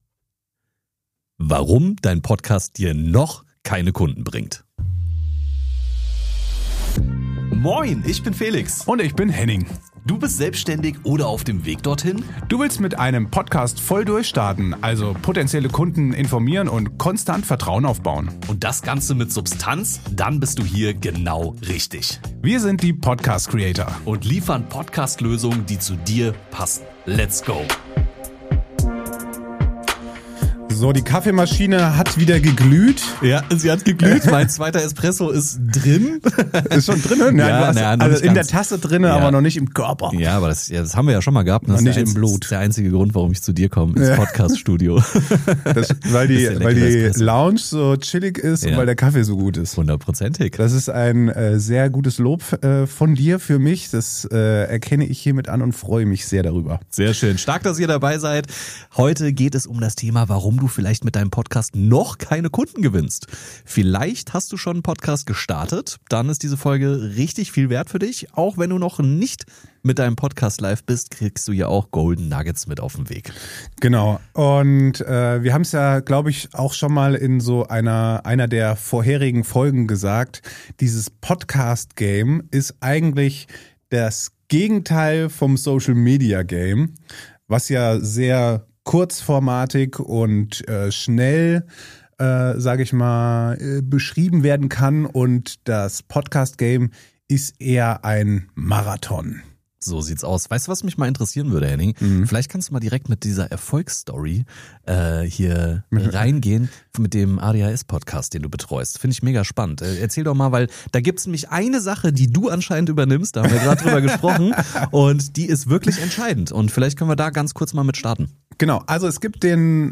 Diese Folge ist besonders wertvoll für: - Coaches - Berater:innen - Selbstständige - Unternehmer:innen - Expert:innen im deutschsprachigen Raum Aufgenommen in Deutschland für Hörerinnen und Hörer in Deutschland, Österreich und der Schweiz.